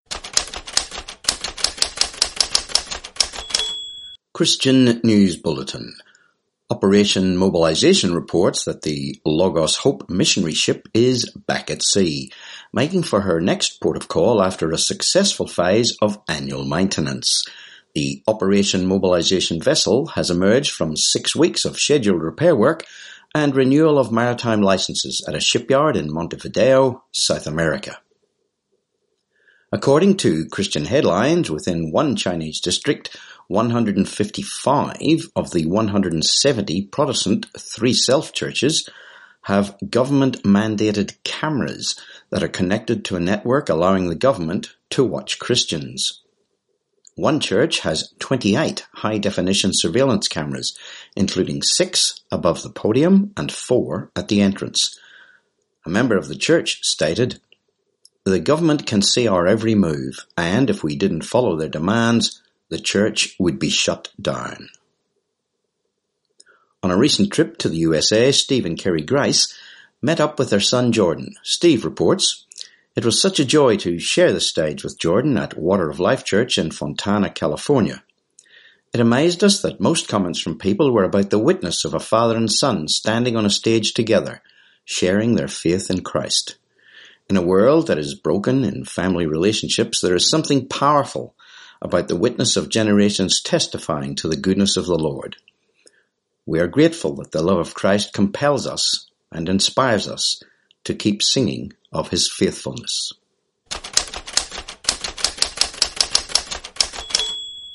23Jun19 Christian News Bulletin